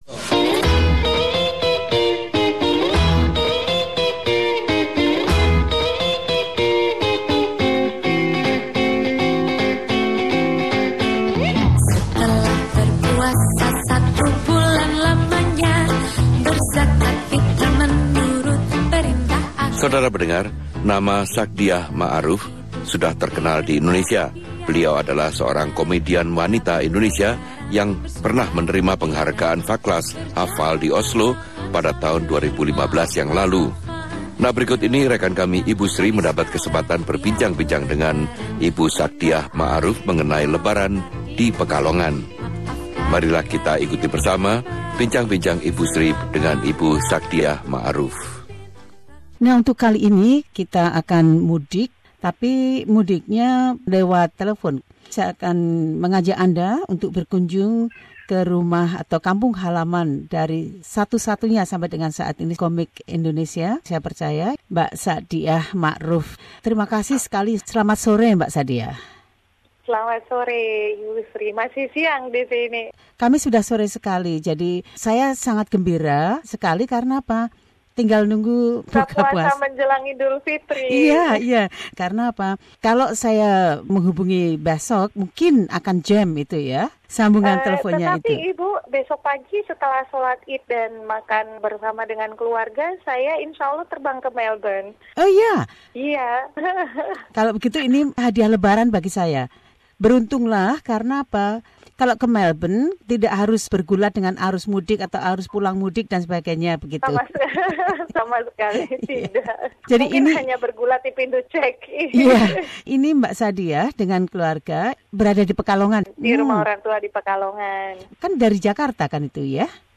Pelawak muda Indonesia Sakdiyah Maruf berbicara tentang rasa gembiranya mudik ke kampung halamannya seusai puasa utnuk merayakan lebaran.